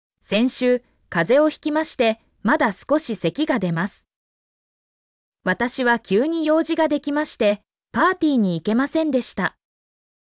mp3-output-ttsfreedotcom-6_coUzPouB.mp3